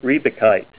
Help on Name Pronunciation: Name Pronunciation: Riebeckite + Pronunciation
Say RIEBECKITE Help on Synonym: Synonym: Crocidolite - asbestos form   ICSD 38218   PDF 19-1061